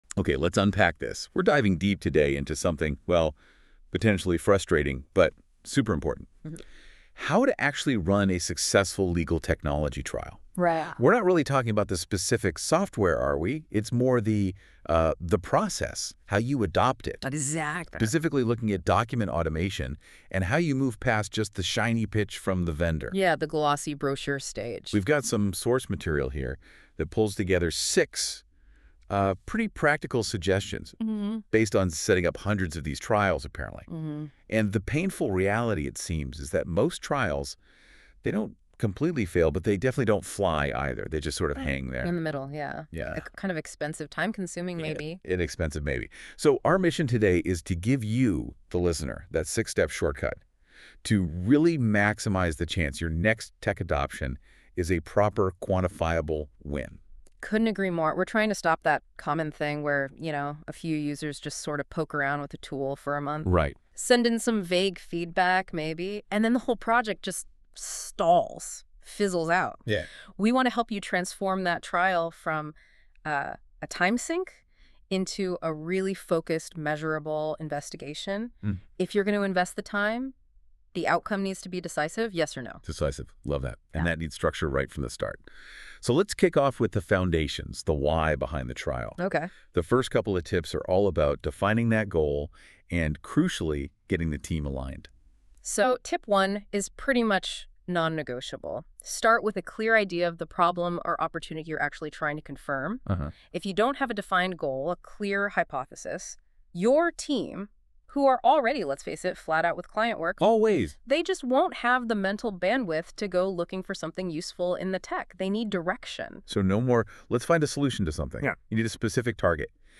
Google NotebookLM If you'd like to hear a Google NotebookLM podcast on this blog, you can do so here .
Google Notebook LM - 6 practical suggestions on how to get the most out of a legal technology trial.m4a